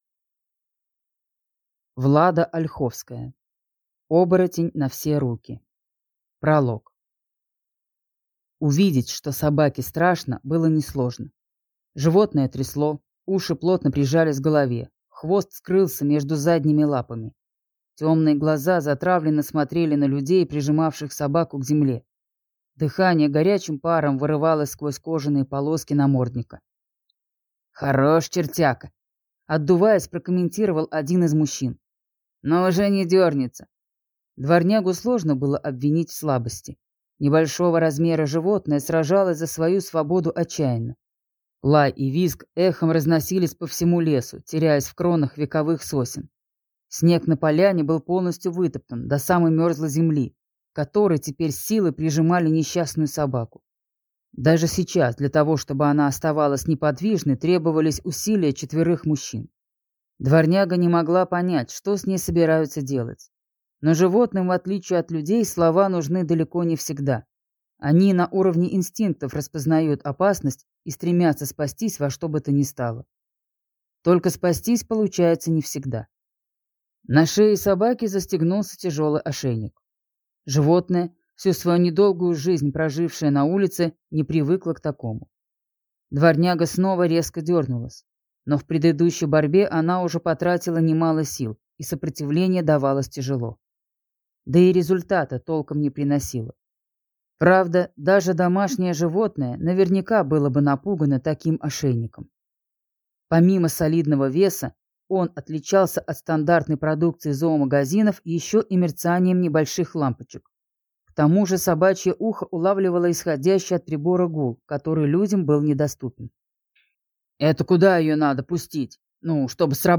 Аудиокнига Оборотень на все руки | Библиотека аудиокниг